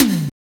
R BAMBTOMHI.wav